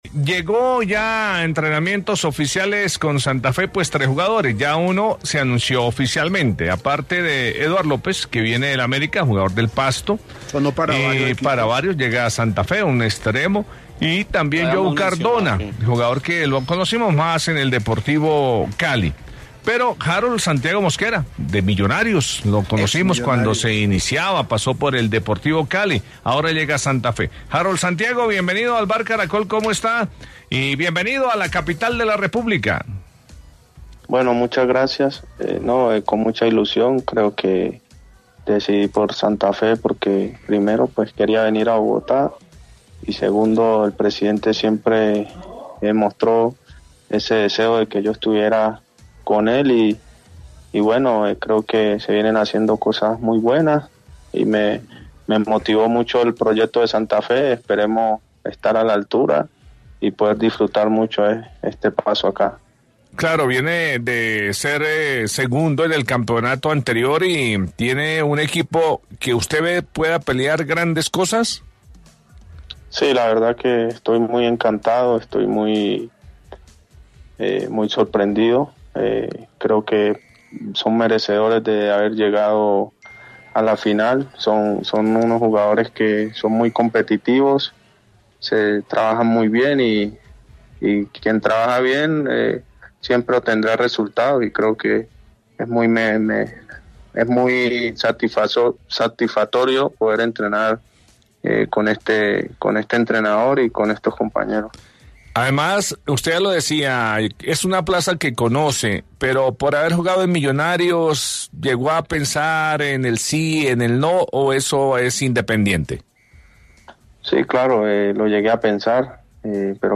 entrevista con el Vbar